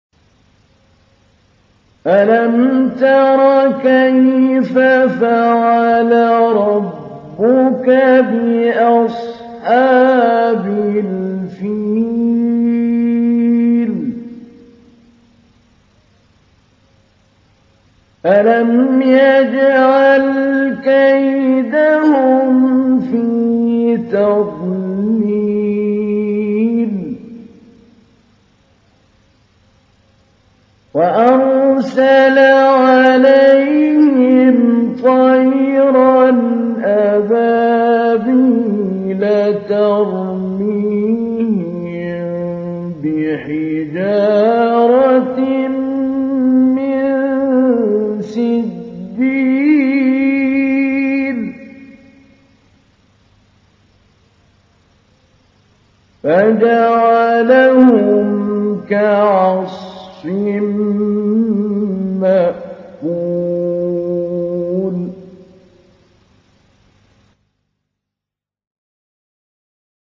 تحميل : 105. سورة الفيل / القارئ محمود علي البنا / القرآن الكريم / موقع يا حسين